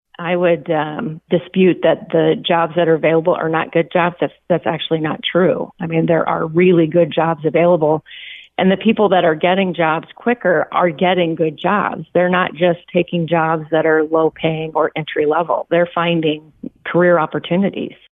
Townsend says Iowa’s job market remains very strong.